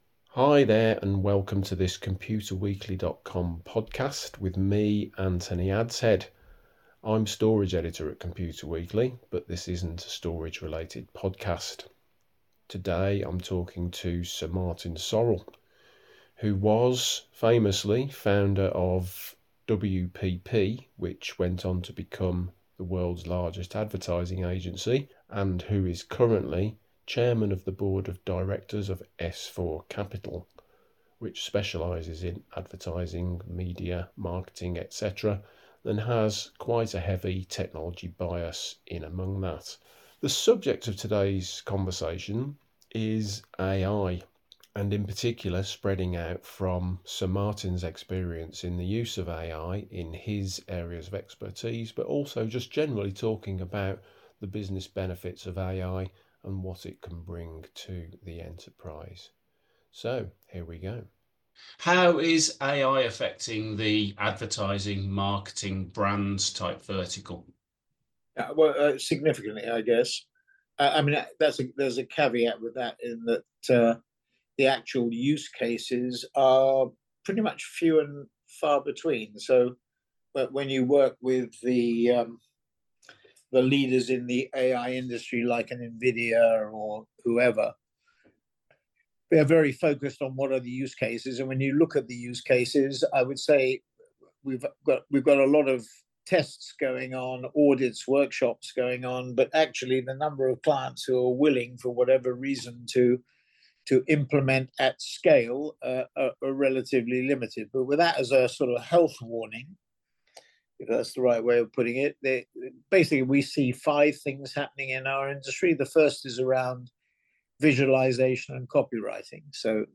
We talk to Sir Martin Sorrell of S4Capital about the use of artificial intelligence in advertising and marketing and how enterprises can take advantage of the productivity it offers.